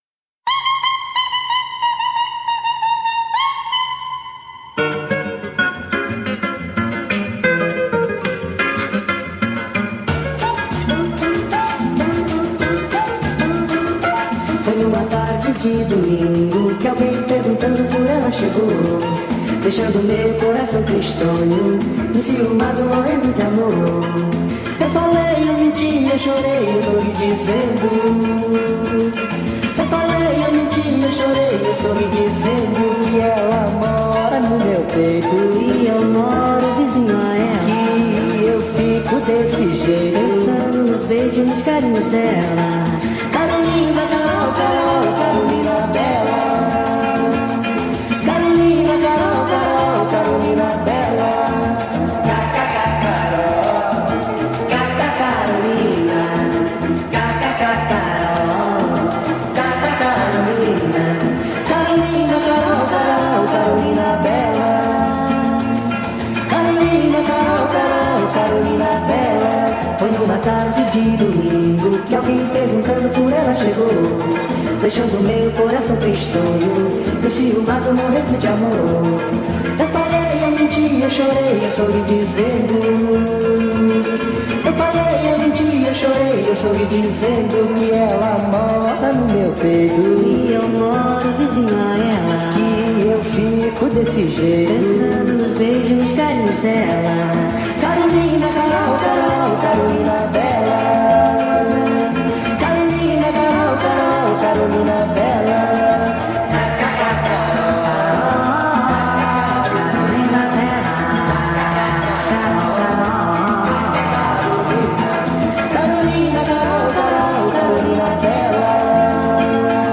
2024-11-02 12:21:15 Gênero: Bossa Nova Views